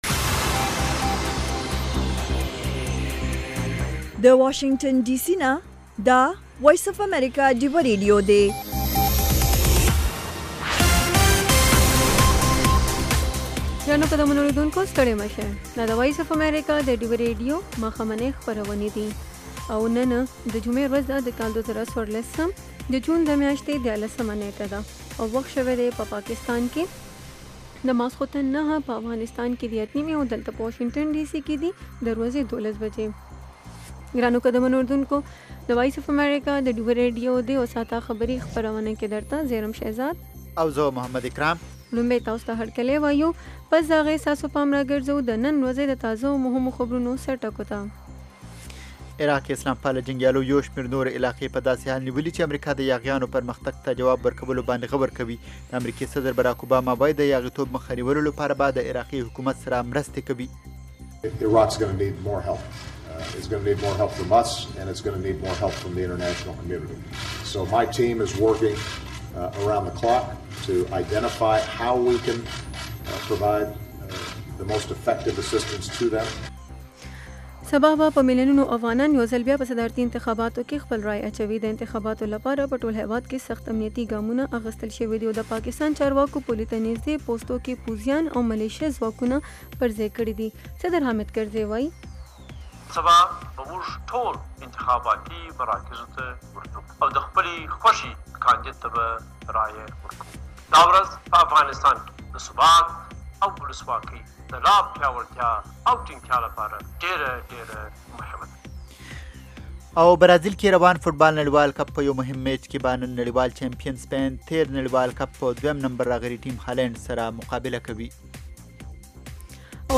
دا یو ساعته خپرونه خونده ورې سندرې لري میلمانه یې اکثره سندرغاړي، لیکوالان، شاعران او هنرمندان وي.